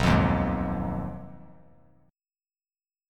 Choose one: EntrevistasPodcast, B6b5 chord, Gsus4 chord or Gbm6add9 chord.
B6b5 chord